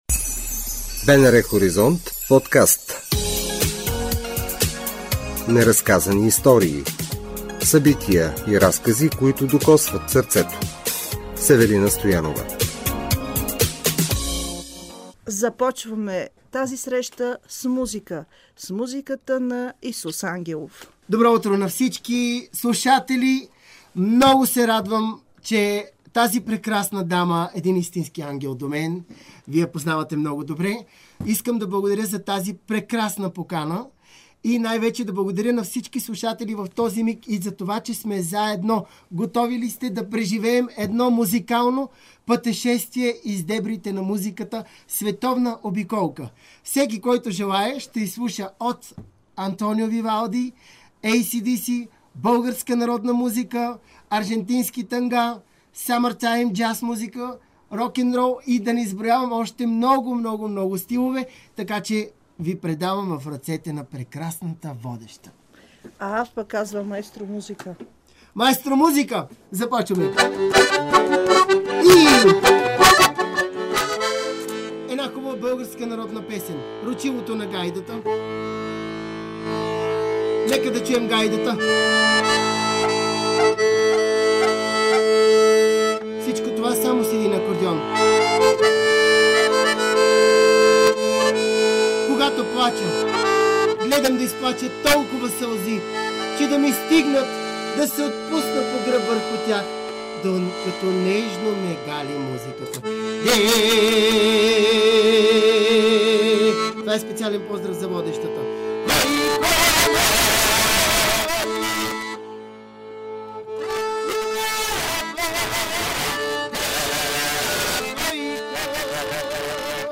В разговора с музиканта: 🟠 Каква е атмосферата на концертите му и по какъв начин се зарежда от музиката и срещите с публиката; 🟠 Какво дете е бил и по какъв начин семейството му е свързано с творчеството и изпълнителското изкуство; 🟠 Кои са някои от артистите, на чието уважение се радва.